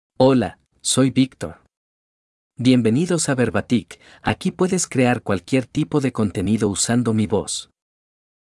MaleSpanish (Puerto Rico)
Victor — Male Spanish AI voice
Victor is a male AI voice for Spanish (Puerto Rico).
Voice sample
Listen to Victor's male Spanish voice.
Victor delivers clear pronunciation with authentic Puerto Rico Spanish intonation, making your content sound professionally produced.